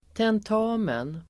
Uttal: [tent'a:men]